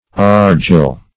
Meaning of argil. argil synonyms, pronunciation, spelling and more from Free Dictionary.
argil.mp3